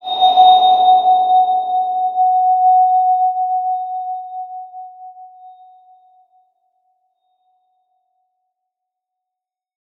X_BasicBells-F#3-mf.wav